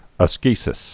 (ə-skēsĭs) or as·ce·sis (-sē-)